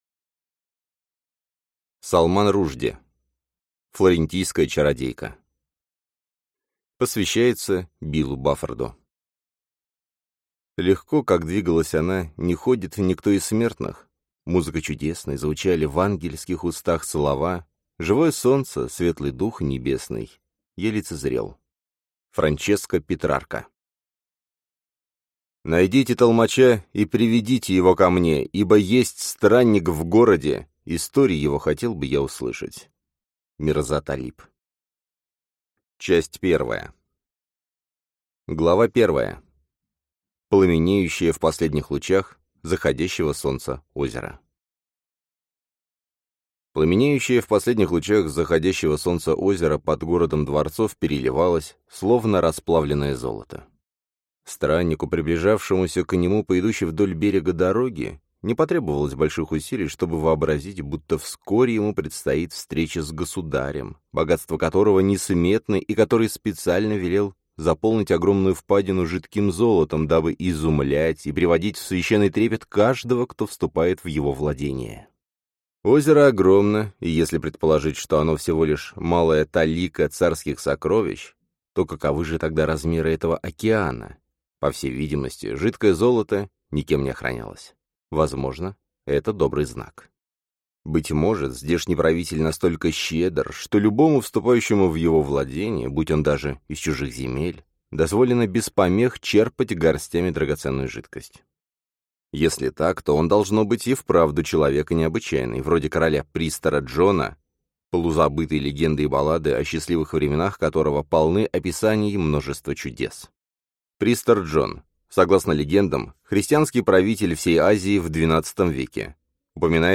Аудиокнига Флорентийская чародейка | Библиотека аудиокниг
Прослушать и бесплатно скачать фрагмент аудиокниги